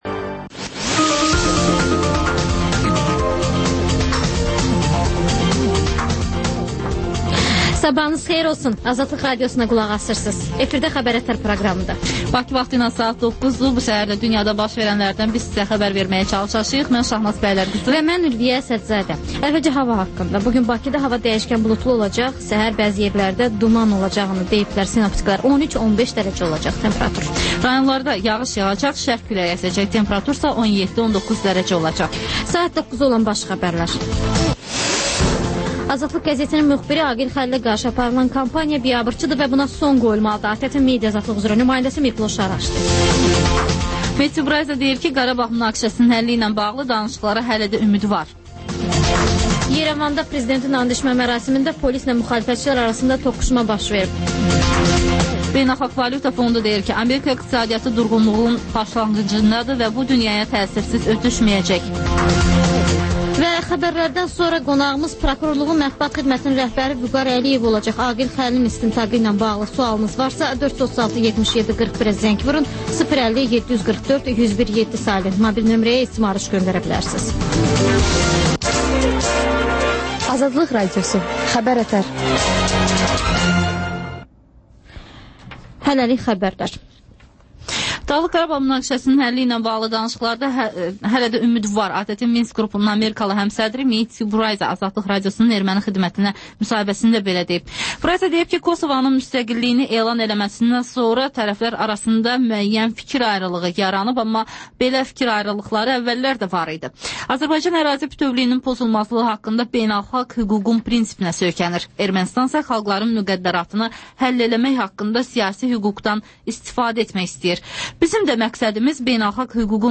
Xəbər-ətər: xəbərlər, müsahibələr, daha sonra ŞƏFFAFLIQ: Korrupsiya haqqında xüsusi veriliş.